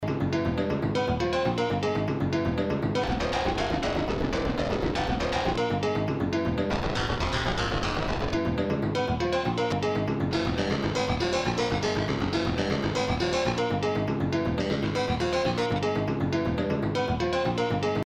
memory K4 MODIFICATION It is possible to circuitbent the k4 for some experimental glitches effect... if you're into digital textures or noises.
demo BENT: 1